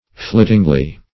Meaning of flittingly. flittingly synonyms, pronunciation, spelling and more from Free Dictionary.
flittingly - definition of flittingly - synonyms, pronunciation, spelling from Free Dictionary Search Result for " flittingly" : The Collaborative International Dictionary of English v.0.48: Flittingly \Flit"ting*ly\, adv.